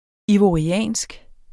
Udtale [ ivoɐ̯iˈæˀnsg ]